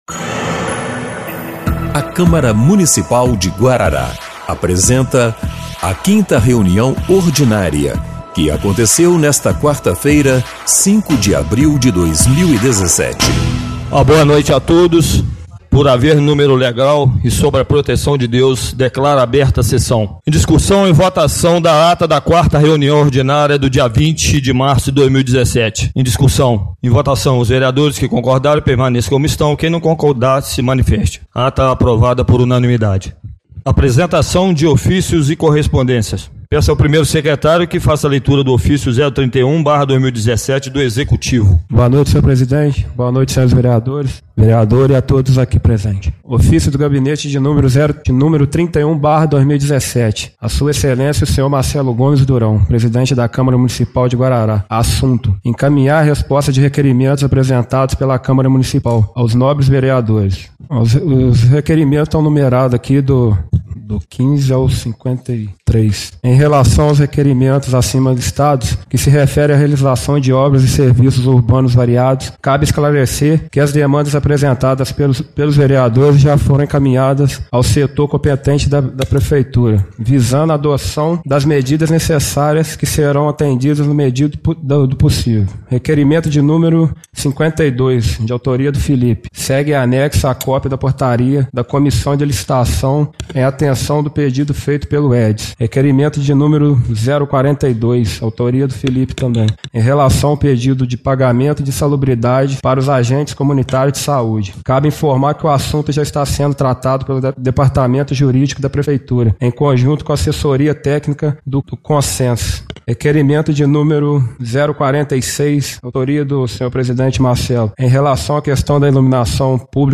5ª Reunião Ordinária de 05/04/2017